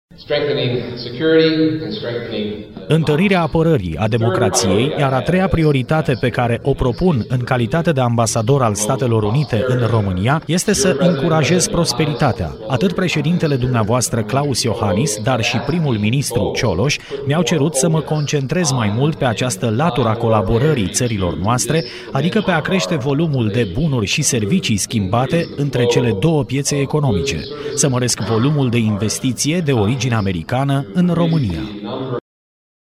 Ambasadorul SUA, Hans Klemm a fost oaspete al Universităţii de vest din Timişoara - Radio România Timișoara
România a făcut paşi importanţi pentru a deveni mai predictibilă pentru investitori dar trebuie să-şi dezvolte în continuare infrastructura şi resursele umane pentru a deveni mai atractivă pentru oamenii de afaceri americani. Precizarea a fost făcută, azi, la Universitatea de Vest Timişoara, de ambasadorul SUA la Bucureşti, Hans Klemm.